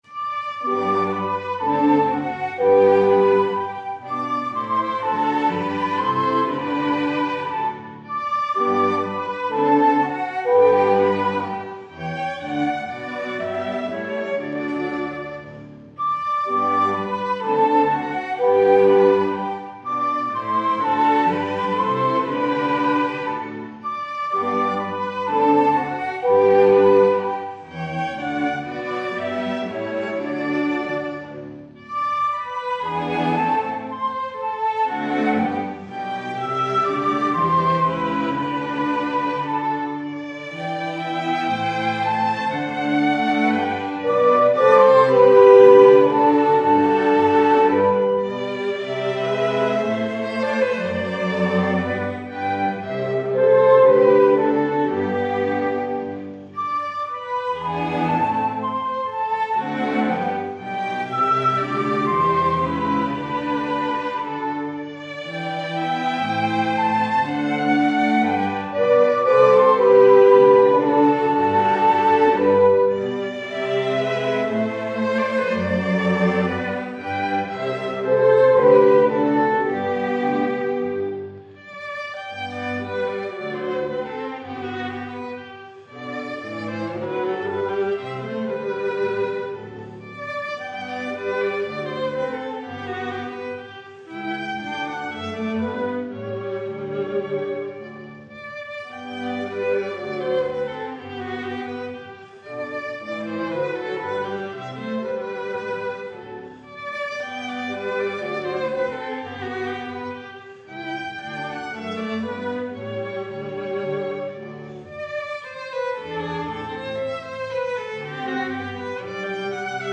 Divertimento 5 in G Major Op 31/5 - H 10/ 4 1. Moderato (Theme with 6 variations) 2. Adagio 3. Minuet - Trios 1 & 2 - Minuet Divertimento 6 in D Major Op 31/6 - H 10/ 2 1.